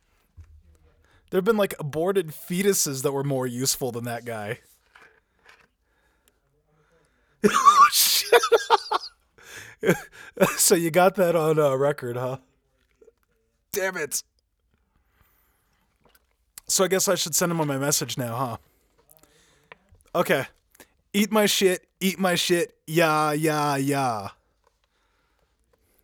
More Vocals_029.wav